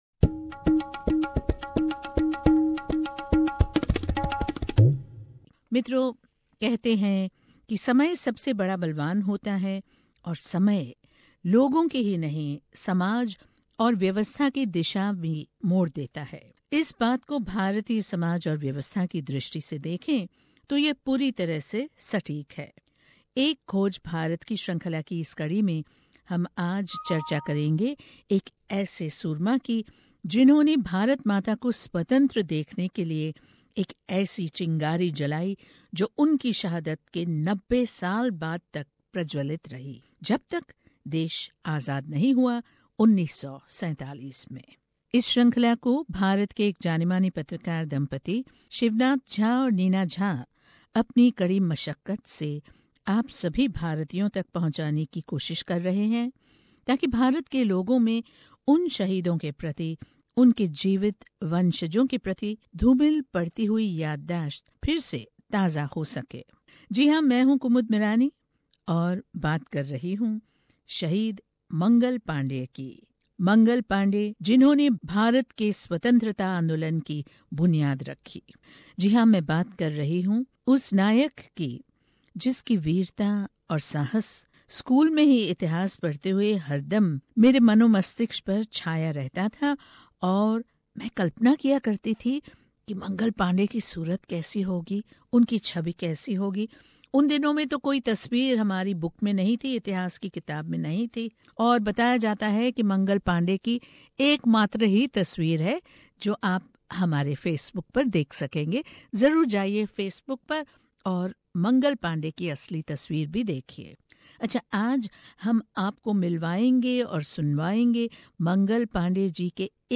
आज शहीद मगंल पांडेय का जन्मदिन है. इस मौके पर पेश है उनके वंशजों से बातचीत.